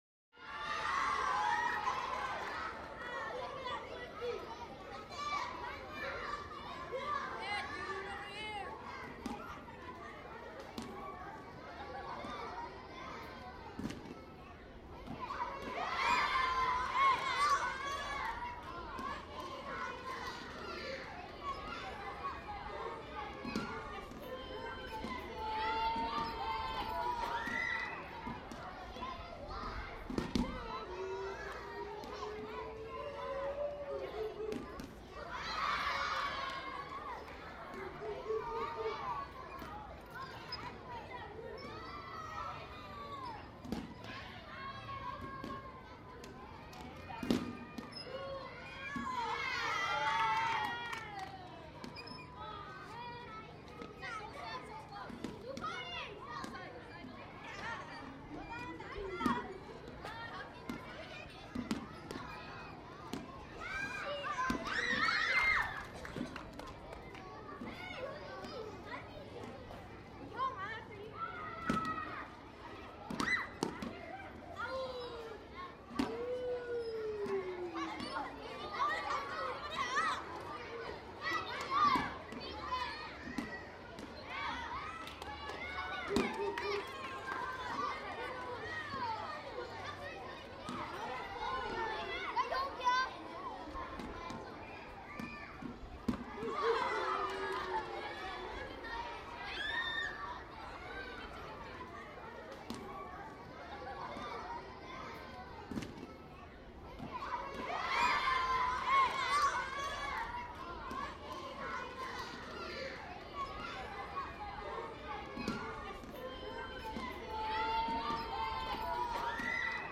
На этой странице собраны звуки, характерные для детского лагеря: смех ребят, шум игр на свежем воздухе, вечерние посиделки с гитарой и другие атмосферные моменты.
Детский смех и голоса в лагере